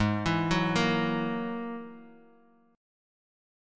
Ab7sus2#5 Chord
Listen to Ab7sus2#5 strummed